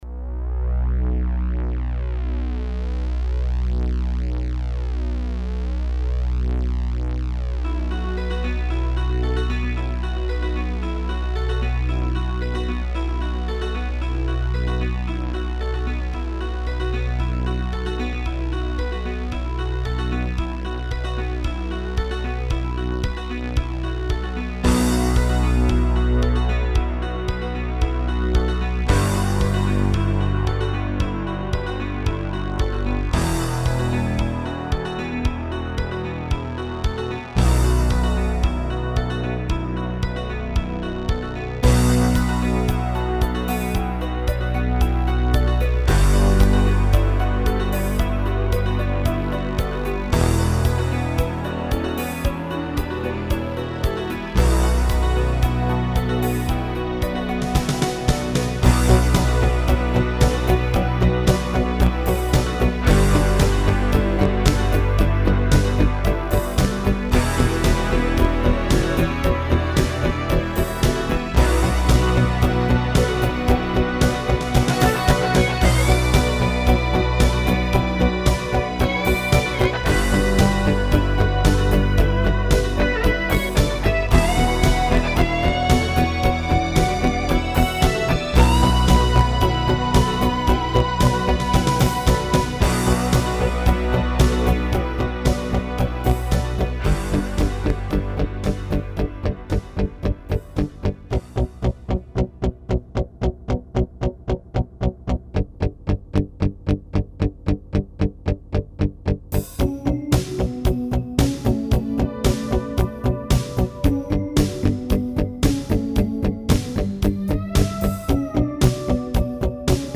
MIDIfile Demo Reproduced via YOUR MIDI System
Selected sound: Acoustic Grand Piano
Selected sound: Acoustic Bass
Selected sound: Overdriven Guitar
Selected sound: Acoustic Guitar (steel)
Selected sound: Polysynth Pad
Selected sound: String Ensemble 2
Selected sound: Electric Piano 1
Selected sound: Orchestral Harp
Selected sound: Drumset
Selected sound: Harpsichord
Selected sound: Guitar harmonics
Selected sound: Muted Guitar
Selected sound: Choir Pad
Selected sound: Metallic Pad